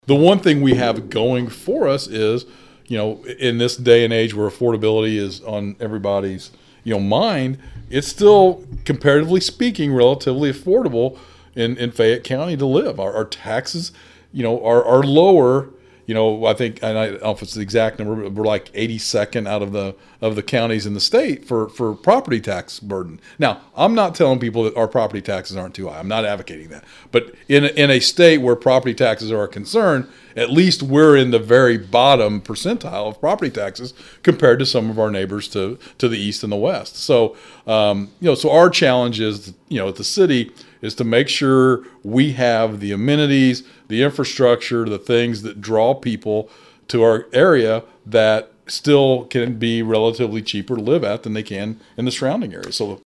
Vandalia Mayor discussing more on housing in the community
Speaking on our podcast “Talking About Vandalia,” Mayor Doug Knebel says there are some possible reasons to believe that this will just be a one year situation.  But, he says they know housing is an issue in the community.